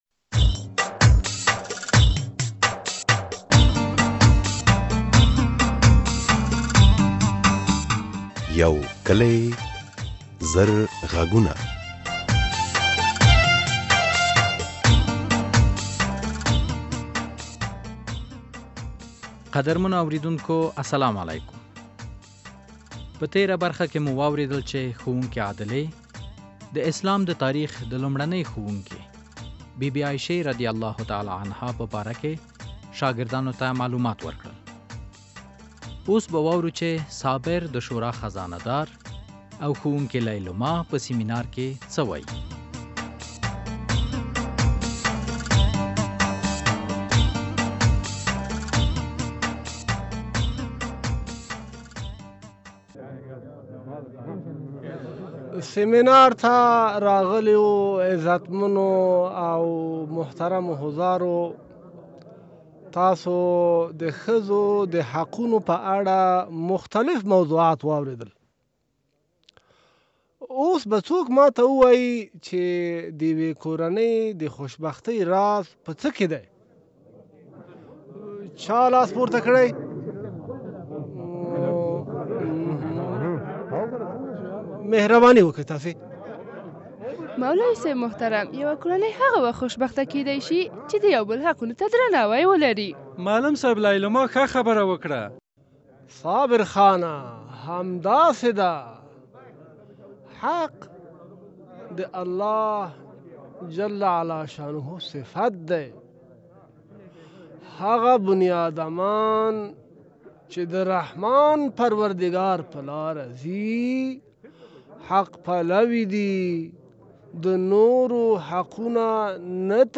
د یو کلي زرغږونو ډرامې ۱۹۲ برخه خپریدو ته چمتو ده چې په یوه کلي کې دا او ورته موضوعات پکې...